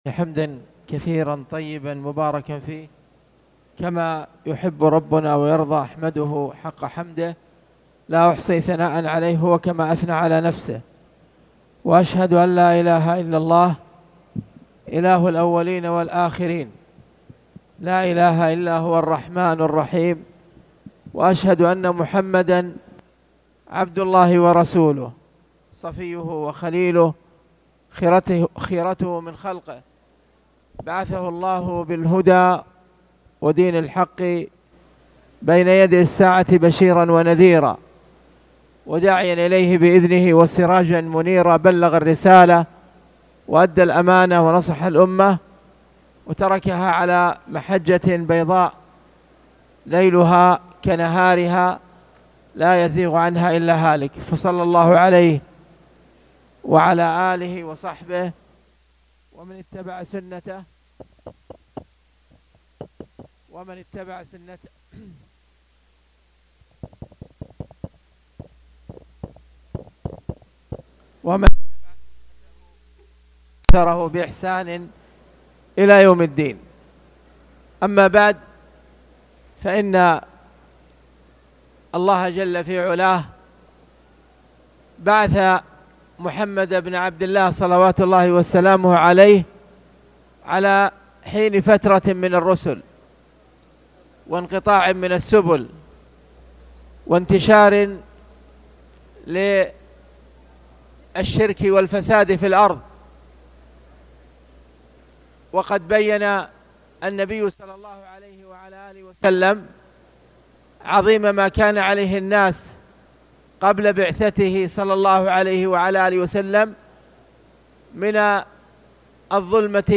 تاريخ النشر ٤ جمادى الأولى ١٤٣٨ هـ المكان: المسجد الحرام الشيخ: خالد بن عبدالله المصلح خالد بن عبدالله المصلح باب تعظيم جناب التوحيد